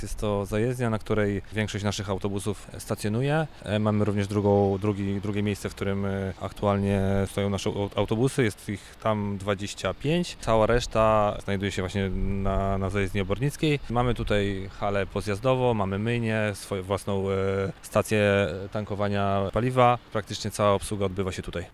Radio Rodzina odwiedziło zajezdnię autobusową przy ul. Obornickiej i porozmawiało o tych i innych tematach z pracownikami MPK Wrocław.